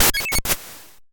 break.ogg